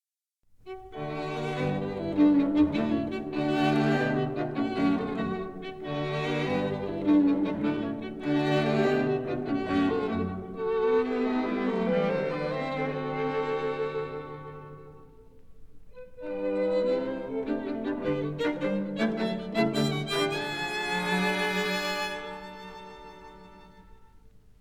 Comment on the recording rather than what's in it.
Stereo recording Studios,30th Street, New York City